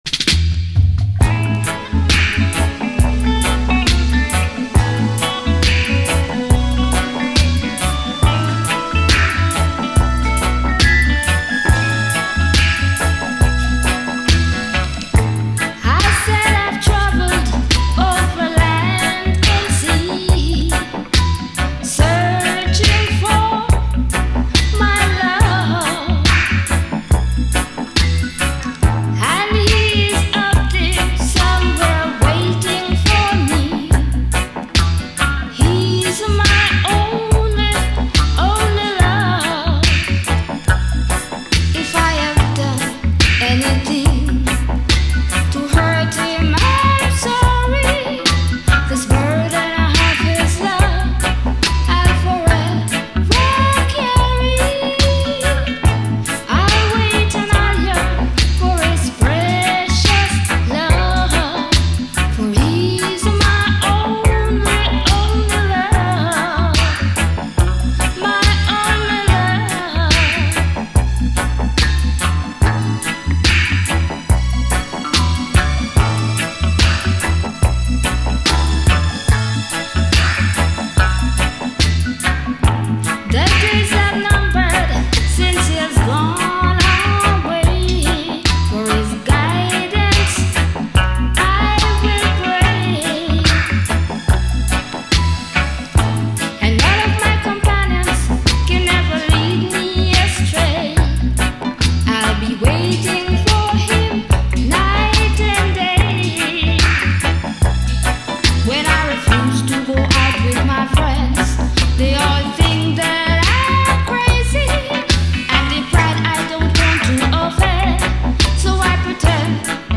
REGGAE
ジャマイカ産80’Sフィメール・ラヴァーズ・ロック！
フワフワしたシンセ使いが綺麗、かつオーセンティックな味わいのナイス・チューン！B面はダブ・ヴァージョン。